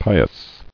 [pi·ous]